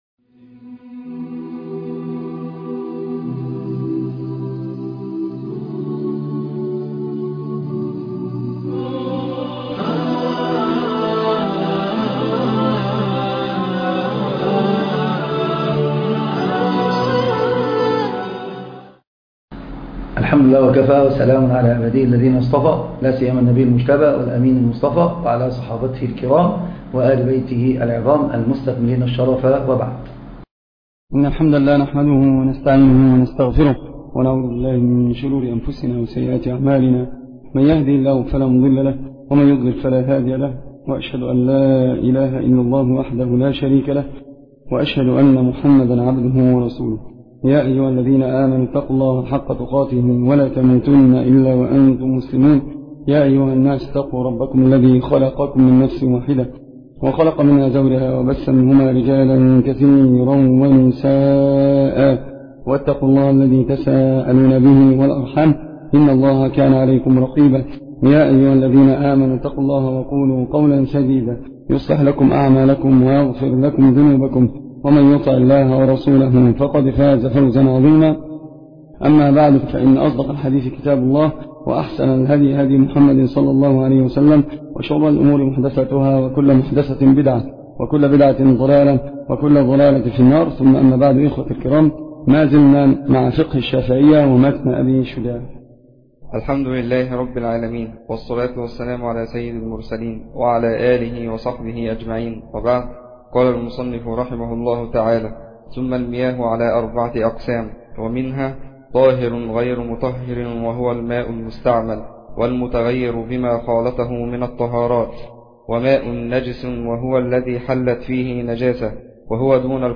الدرس (4) الدورة الثانية - شرح متن ابي شجاع